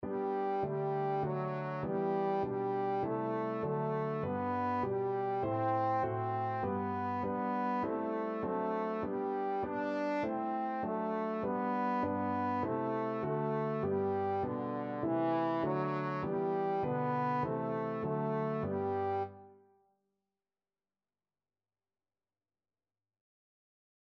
Classical Tallis, Thomas All Praise to Thee, My God, This Night (Tallis Canon) Trombone version
4/4 (View more 4/4 Music)
G major (Sounding Pitch) (View more G major Music for Trombone )
Trombone  (View more Easy Trombone Music)
Classical (View more Classical Trombone Music)